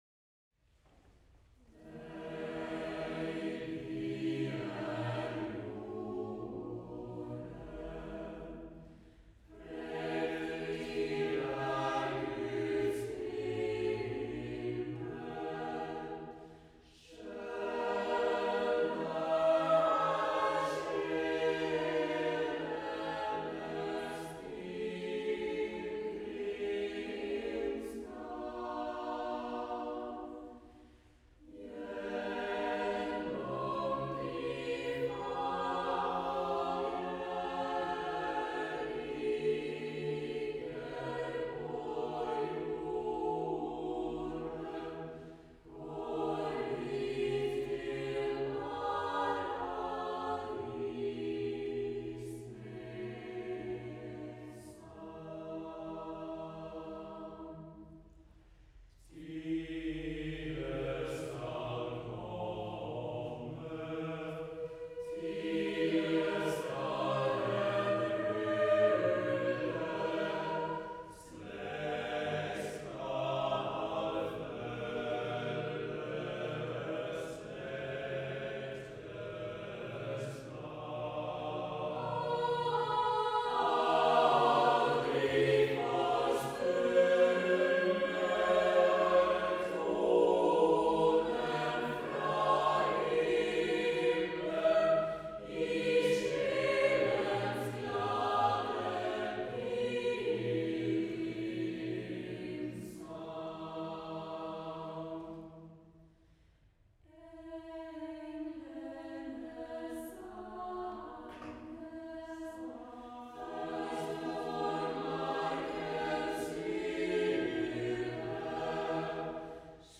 Korsfarersang fra det 12te årh./B. S. Ingemann, arr. Thomas Beck
Deilig_er_jorden_arr_Thomas_Beck_Mariakirken_desember_2017.wav